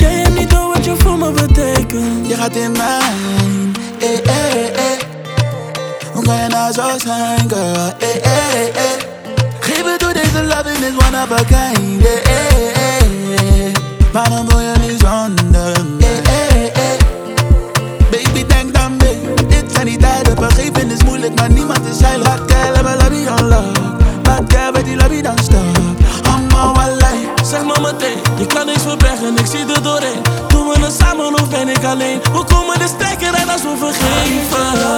Жанр: Поп / Африканская музыка
# Afro-Pop